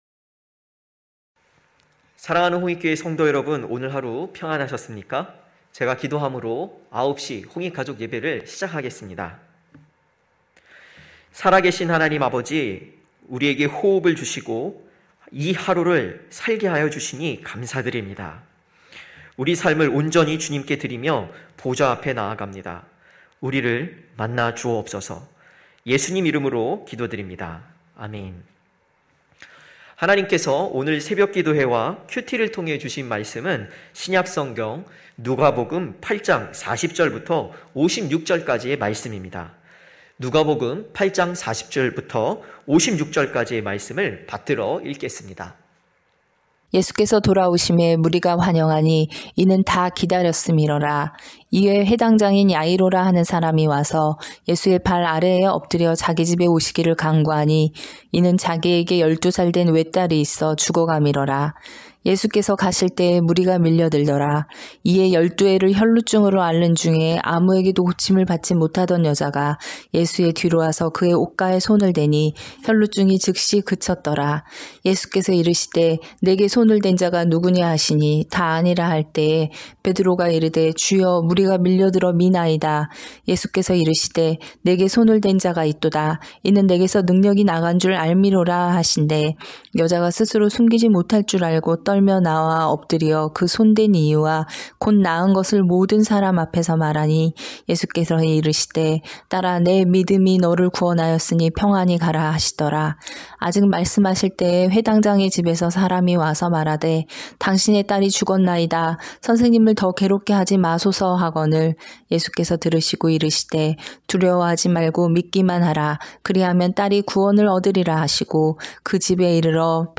9시홍익가족예배(1월25일).mp3